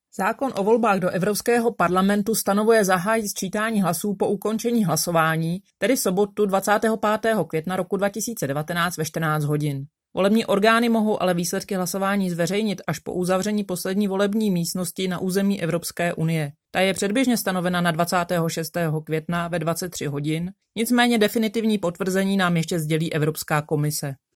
Vyjádření Evy Krumpové, 1. místopředsedkyně ČSÚ, soubor ve formátu MP3, 953.48 kB